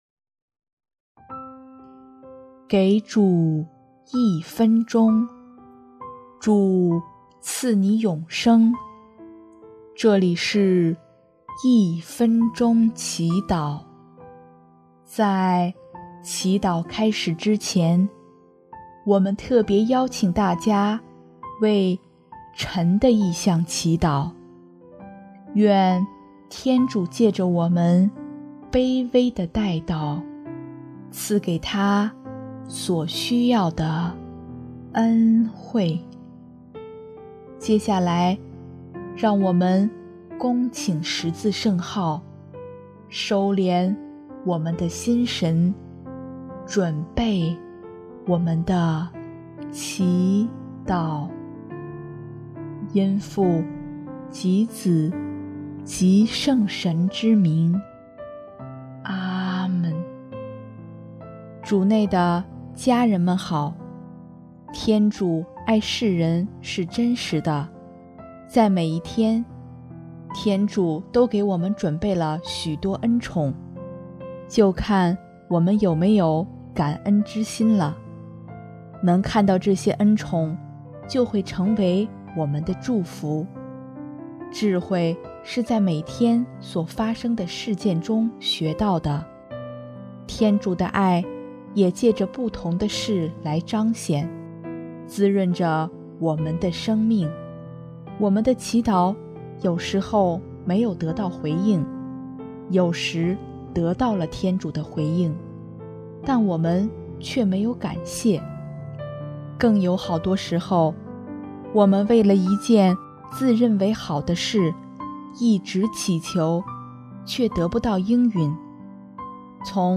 音乐： 主日赞歌